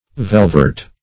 Velveret \Vel`ver*et"\, n. A kind of velvet having cotton back.